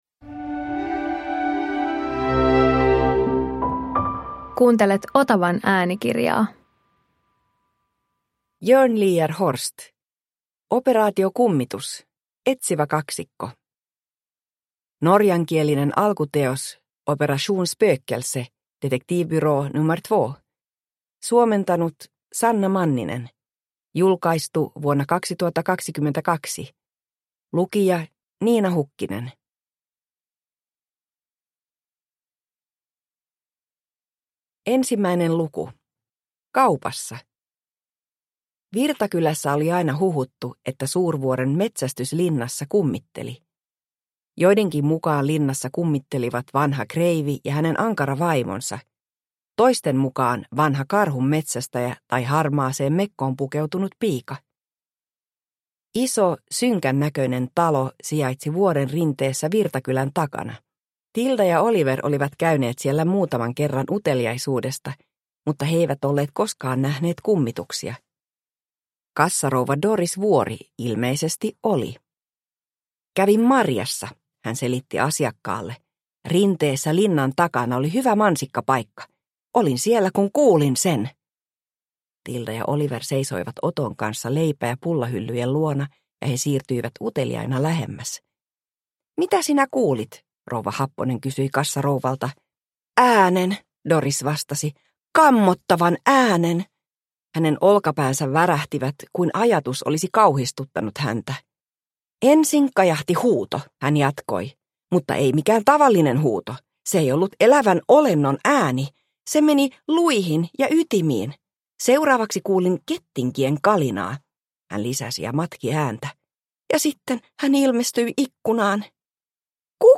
Operaatio Kummitus – Ljudbok – Laddas ner